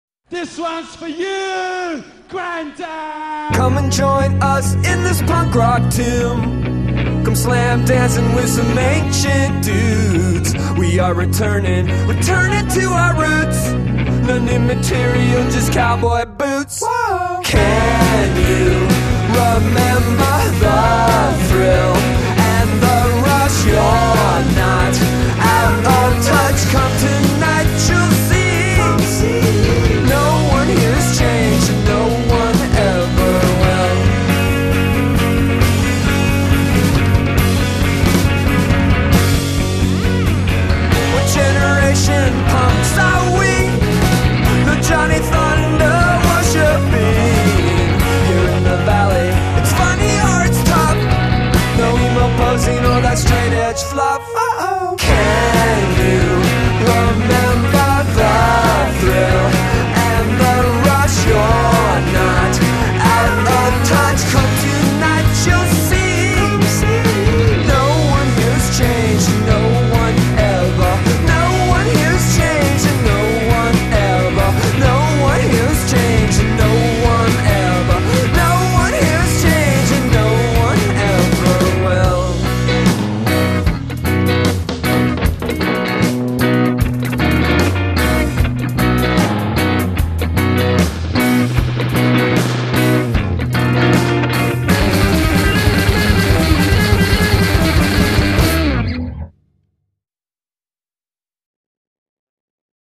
è la canzone più breve del nuovo disco
canta con un sorriso